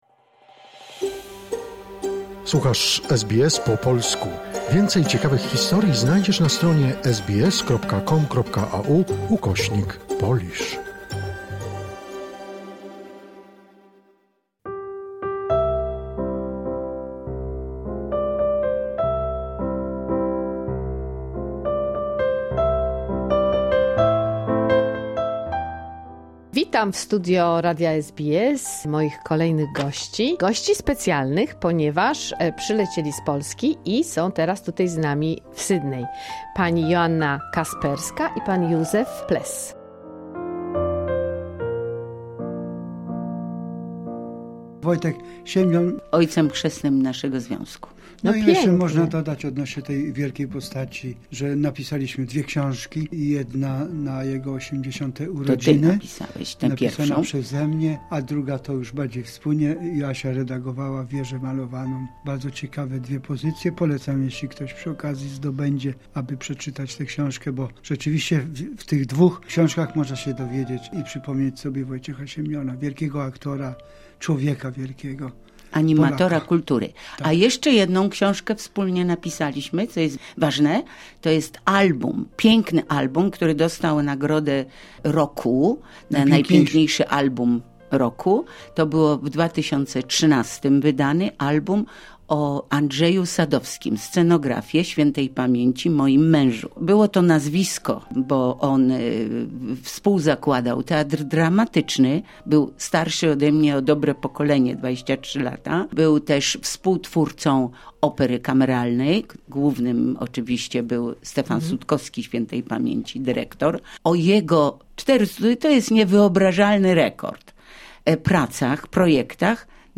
Druga część rozmowy z wyjątkową parą z Polski - aktorką i poetą.